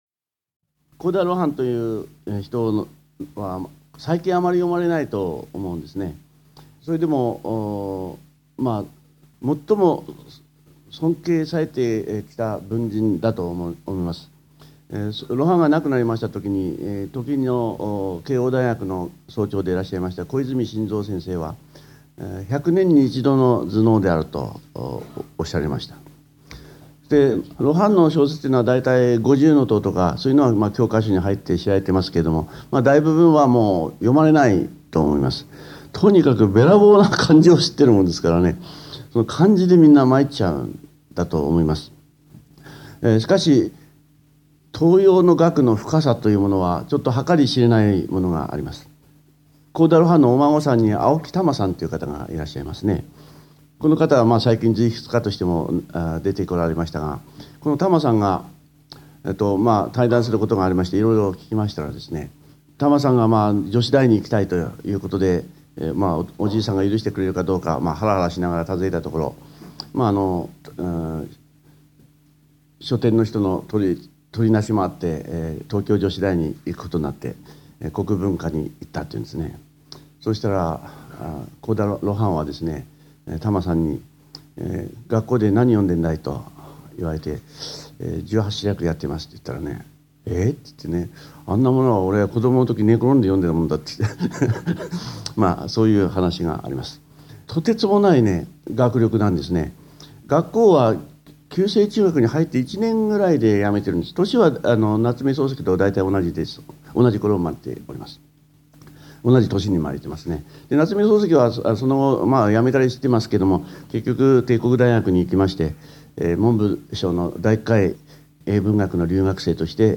※この音声は平成13年に開催した致知出版社主催の「歴史に学ぶ『修己治人』の成功学」での講演を収録したもので、「渡部昇一講演録ＣＤ修養2」第1巻で収録されているものと同じ内容です。